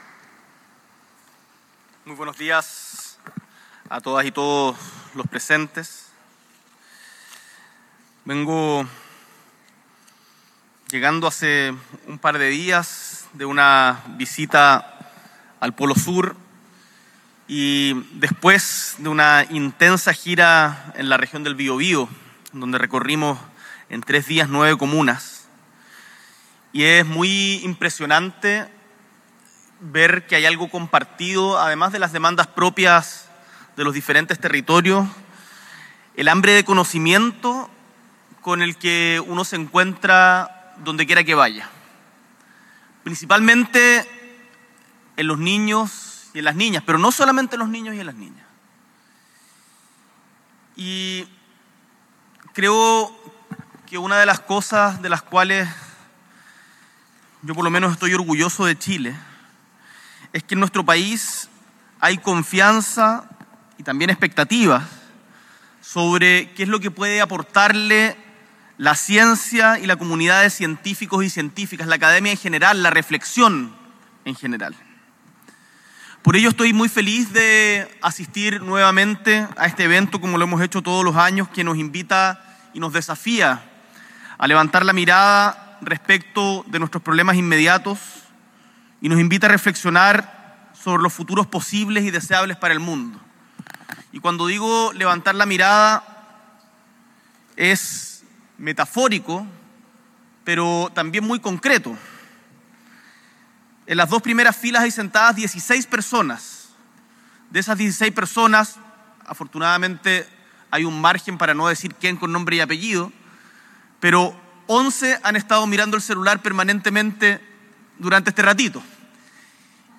S.E. el Presidente de la República, Gabriel Boric Font, encabeza la inauguración del Congreso Futuro 2025.